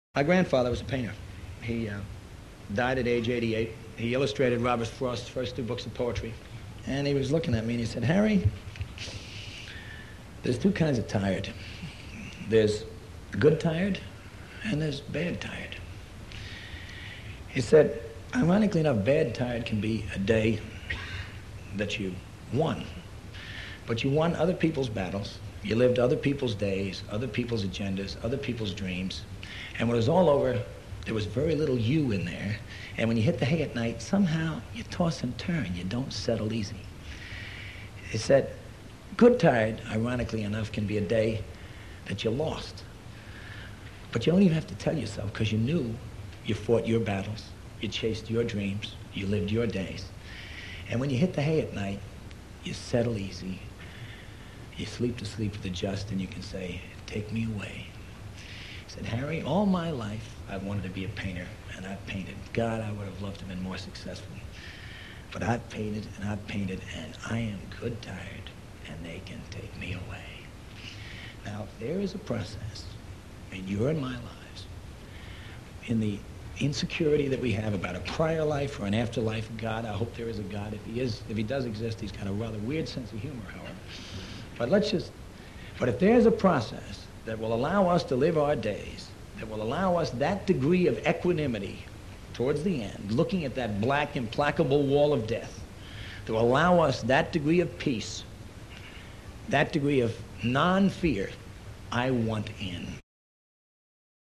The singer song writer Harry Chapin once told the story…